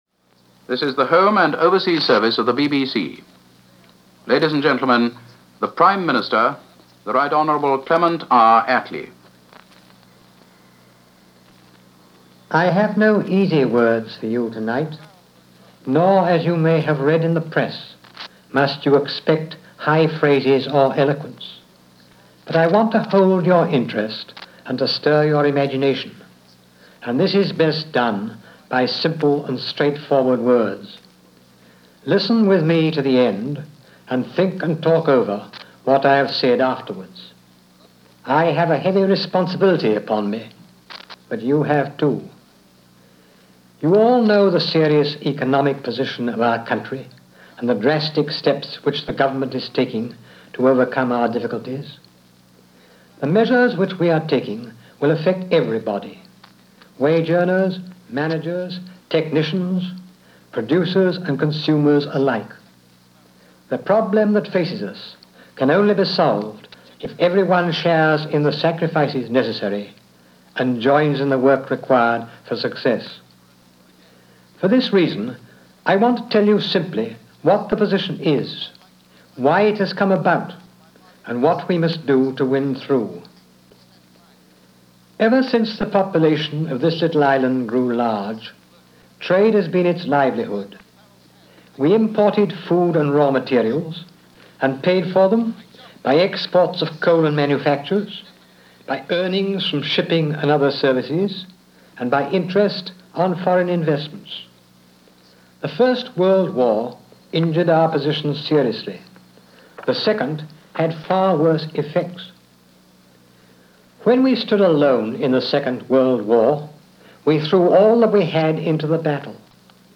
BBC Home And Overseas Service – Address by Prime Minister Clement Atlee